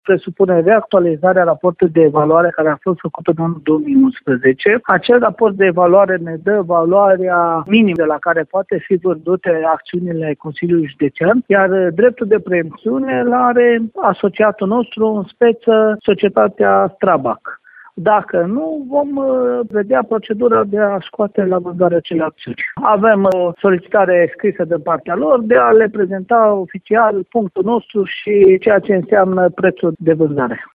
Administraţia judeţeană a demarat deja procedura de înstrăinare a cotei de 30 la sută din capitalul social, spune vicepreşedintele instituţiei Călin Dobra.